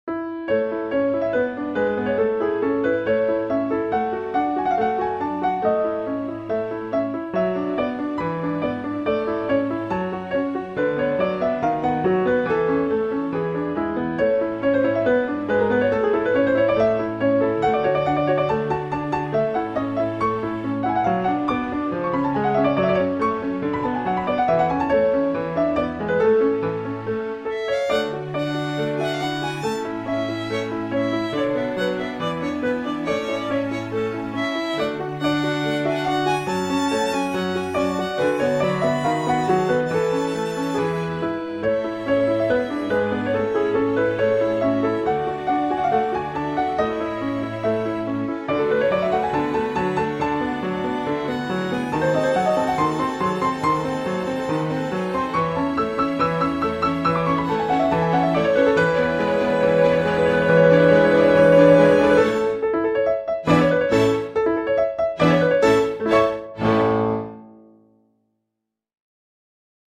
electronic music
Eine Kleine KVR-Musik Imitating famous artists 11 of 50